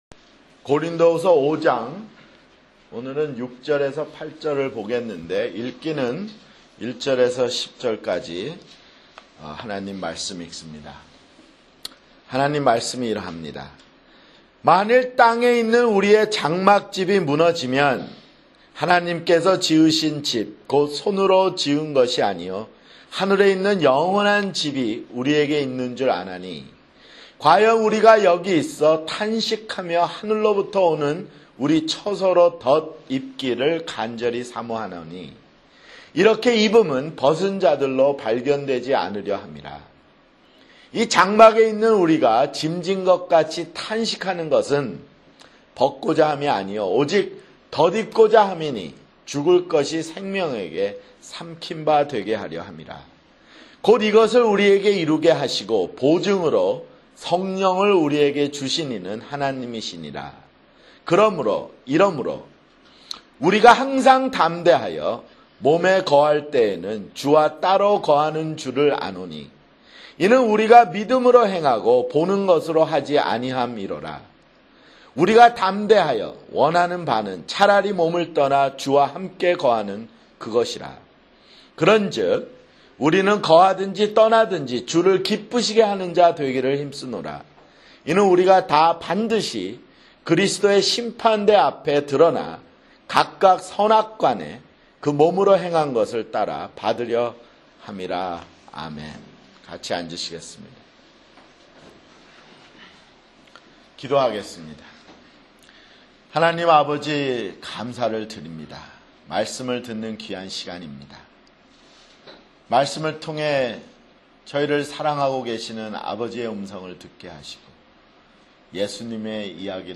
[주일설교] 고린도후서 (26)